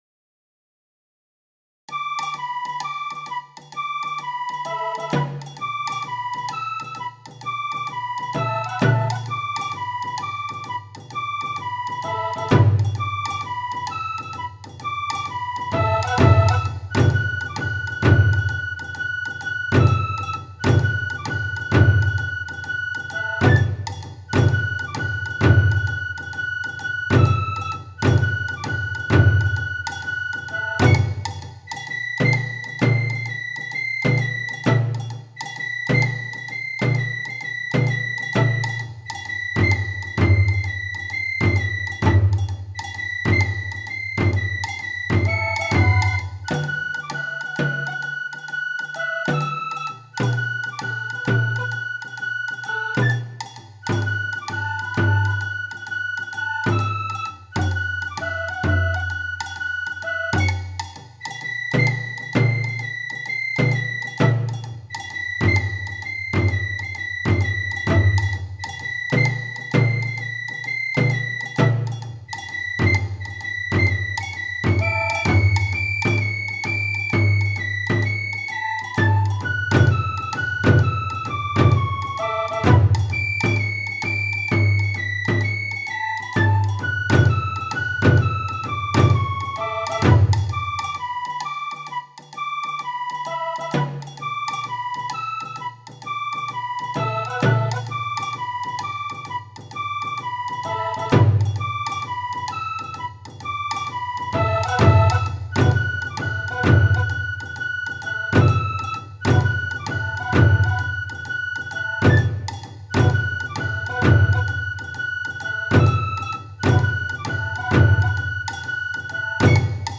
四連符にアクセントを淡々と付ける打ち方です。
淡々と黙々と盛り上げます。
残念ながら実際に太鼓を叩いて録音したものではないのです。
下のサンプルは、三つの音源がよくわかるように、エフェクトはかけていません。
クオリティーが高い。すっきりしている。品位がある。